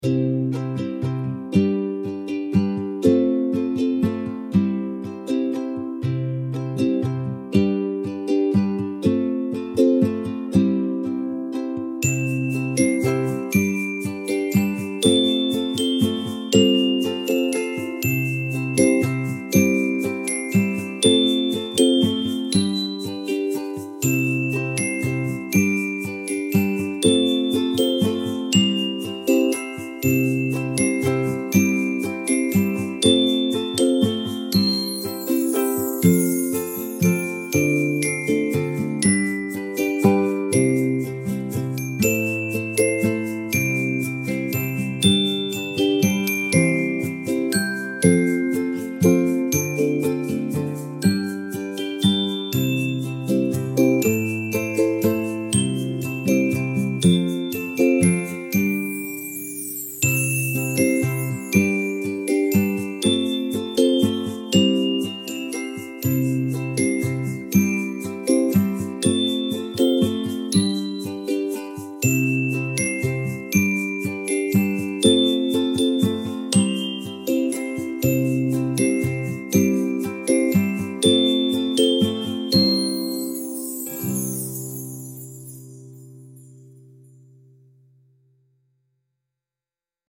happy nursery-style instrumental with gentle bells and acoustic guitar